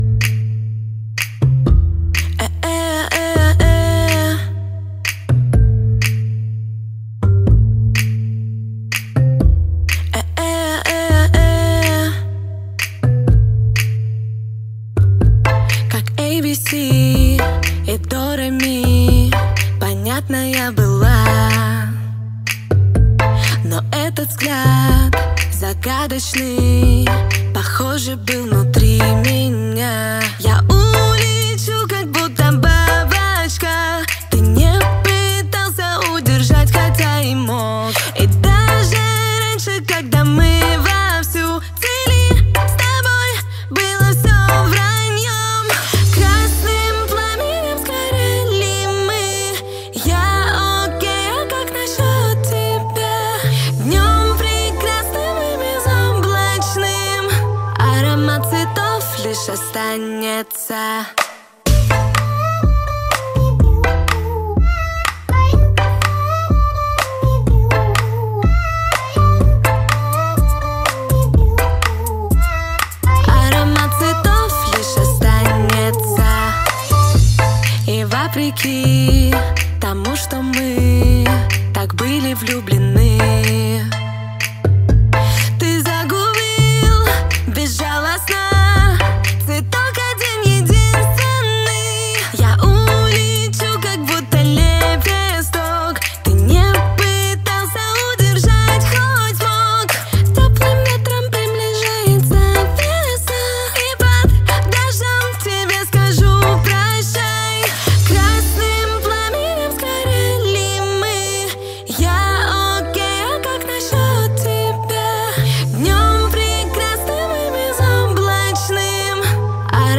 кавер
Russian Cover